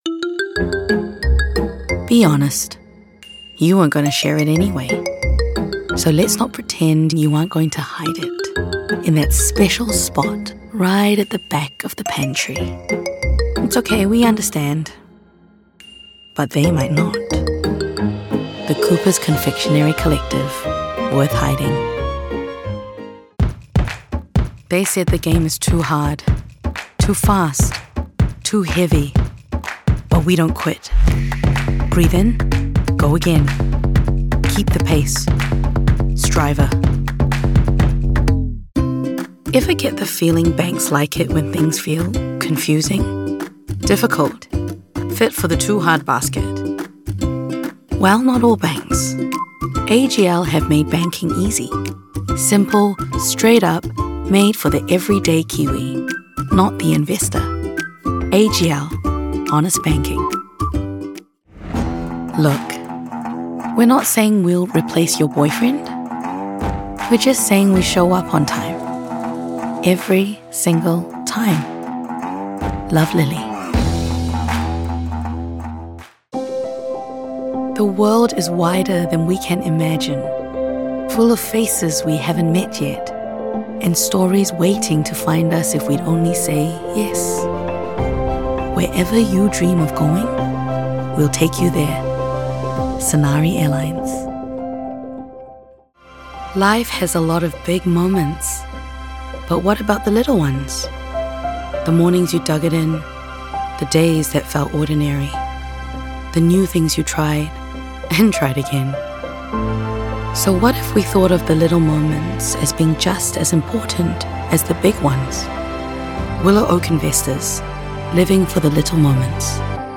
Demo
husky